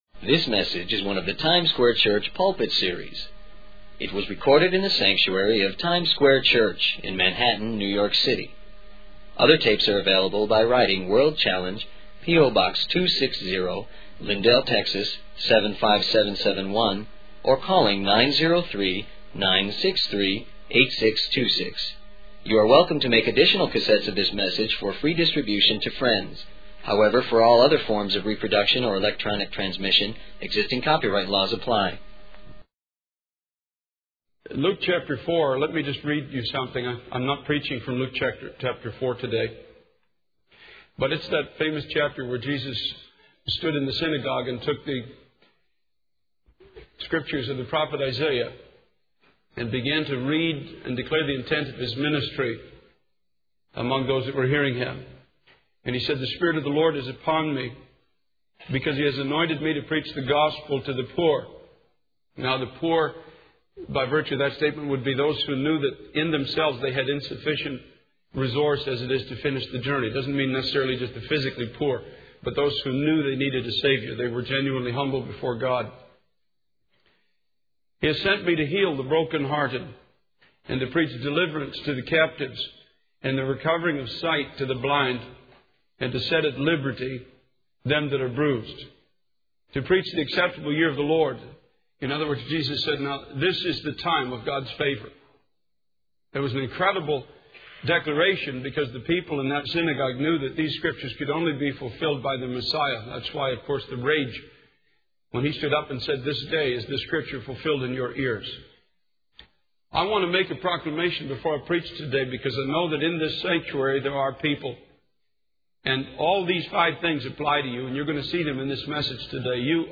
In this sermon, the speaker emphasizes the importance of not following man-made visions and plans, as they can lead to exhaustion and leave one in the wilderness.